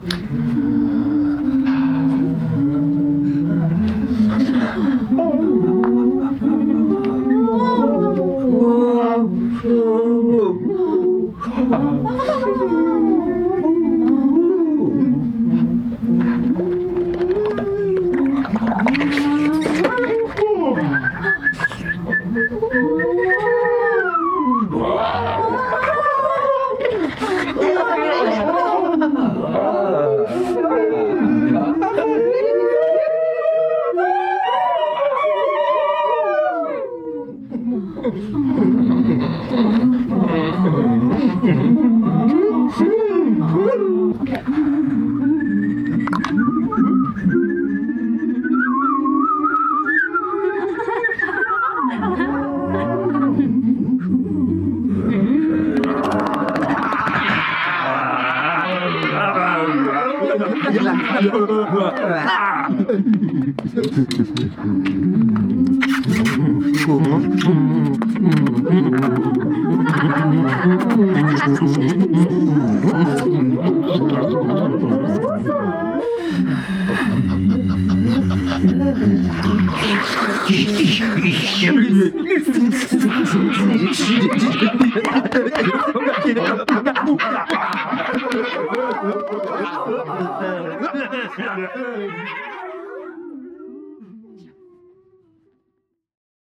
Humans sounding bird breath
bird-breath-experimentation-2018.m4a